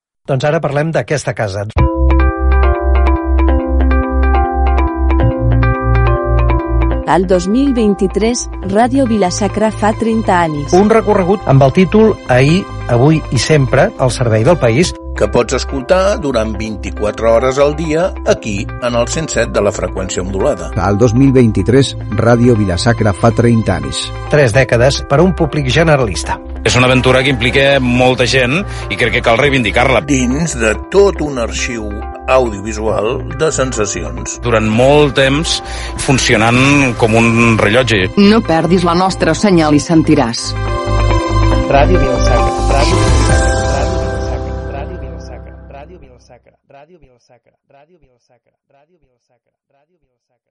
Anunci dels 30 anys de l'emissora l'any 2023